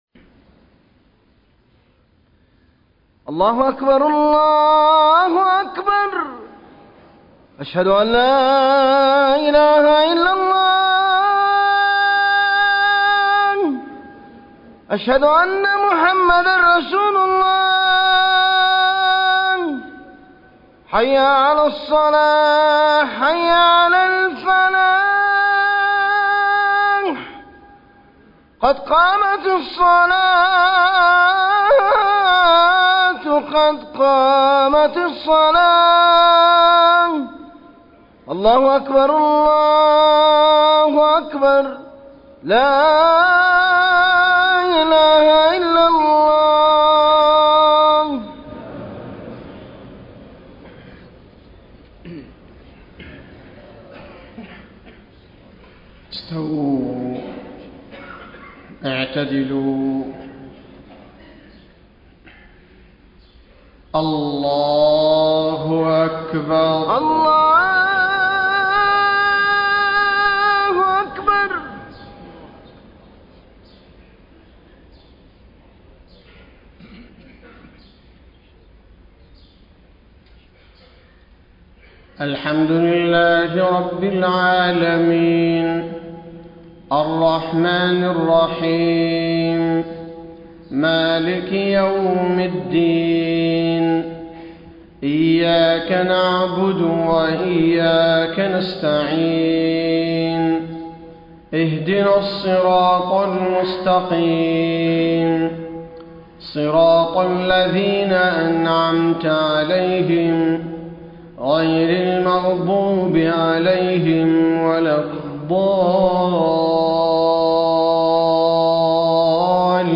صلاة الفجر 2 - 4 - 1434هـ سورة نوح > 1434 🕌 > الفروض - تلاوات الحرمين